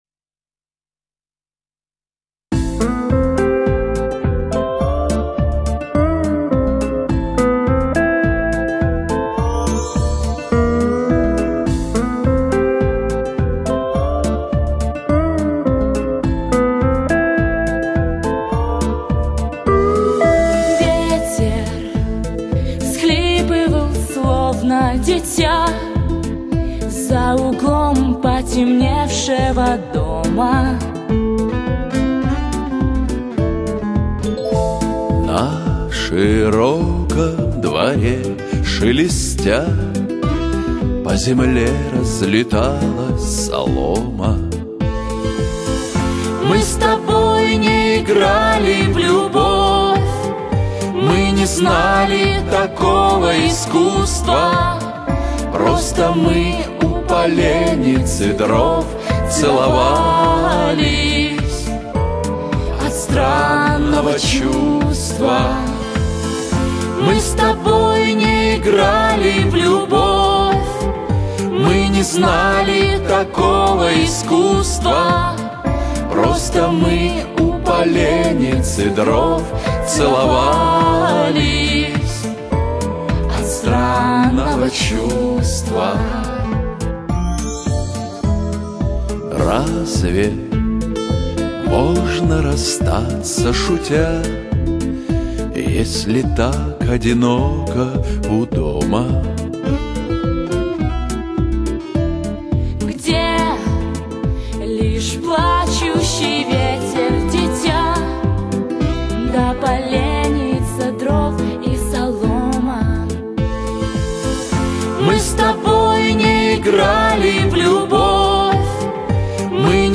Женский вокал